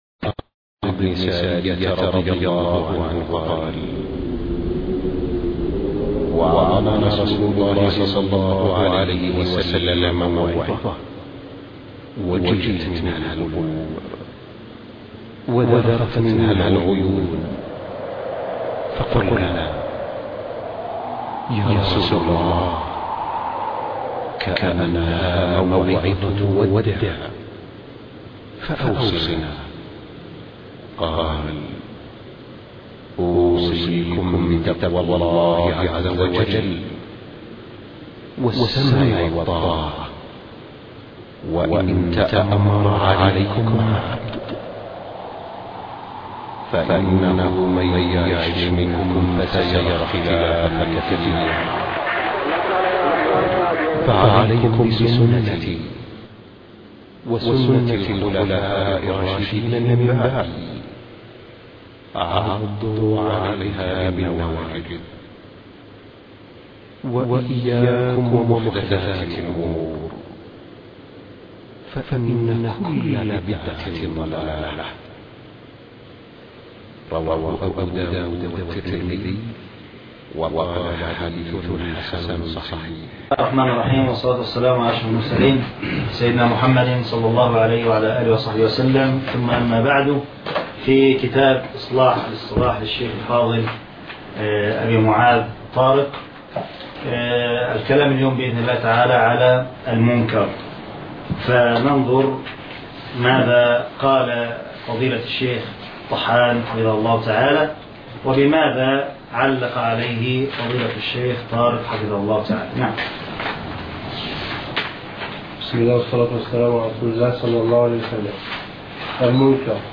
الدرس (27)( مناقشة علمية لكتاب إصلاح الاصطلاح )